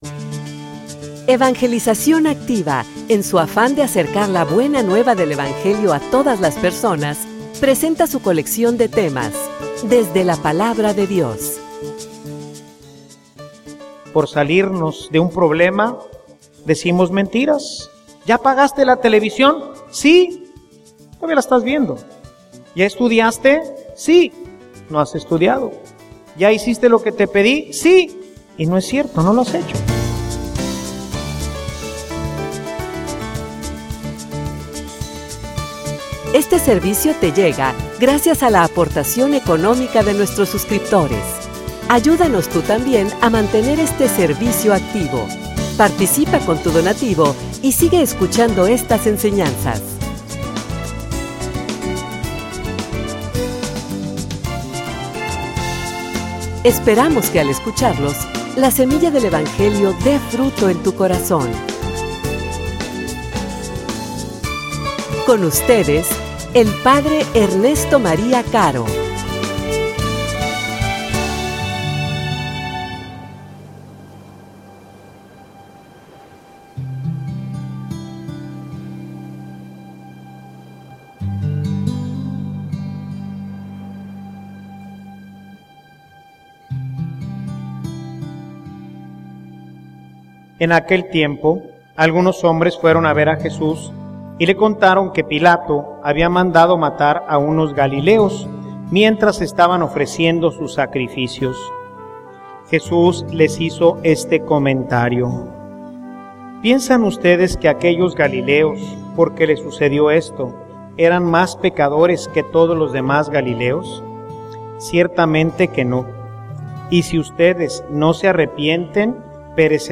homilia_El_reto_de_la_normalidad.mp3